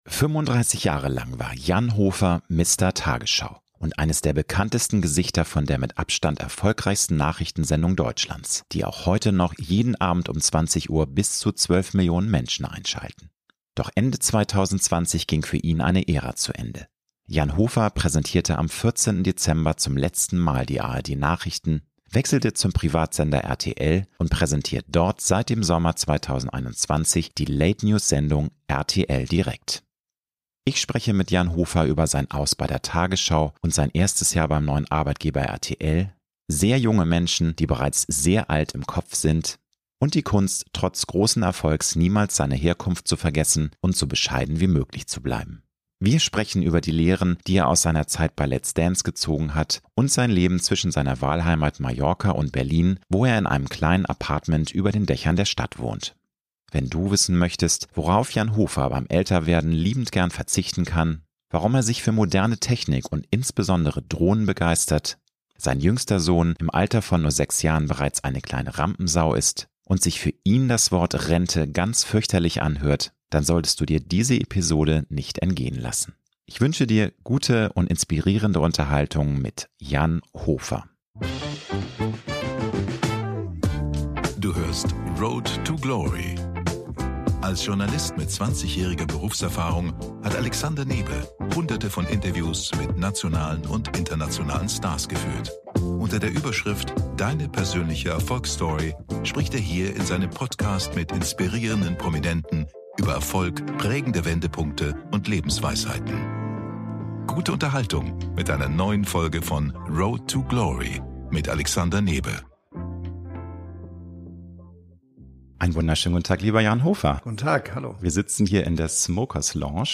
Ich spreche mit Jan Hofer über sein Aus bei der Tagesschau und sein erstes Jahr beim neuen Arbeitgeber RTL, sehr junge Menschen, die bereits sehr alt im Kopf sind und die Kunst, trotz großen Erfolgs niemals seine Herkunft zu vergessen und so bescheiden wie möglich zu bleiben. Wir sprechen über die Lehren, die er aus seiner Zeit bei „Let’s Dance“ gezogen hat und sein Leben zwischen seiner Wahlheimat Mallorca und Berlin, wo er in einem kleinen Apartment über den Dächern der Stadt wohnt.